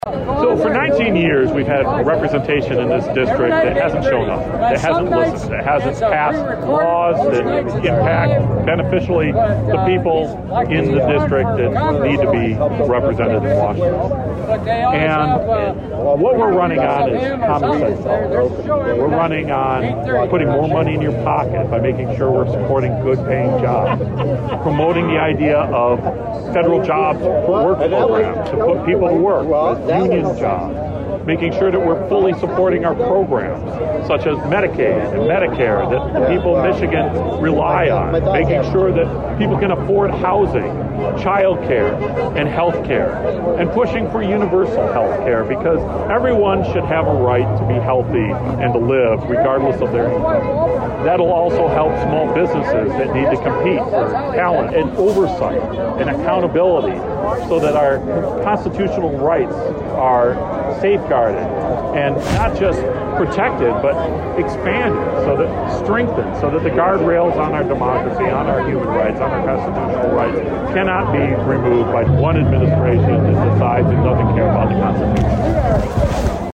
March 30, 2026 Staff Featured Slider, News Daypop